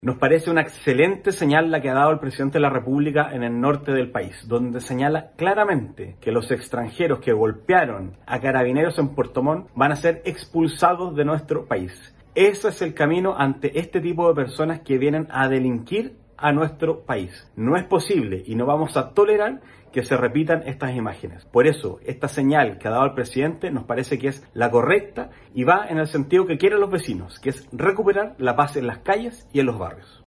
Por su parte, el diputado por el Distrito 26, Alejandro Bernales, señaló que el anuncio realizado por el Presidente es una excelente señal para el país: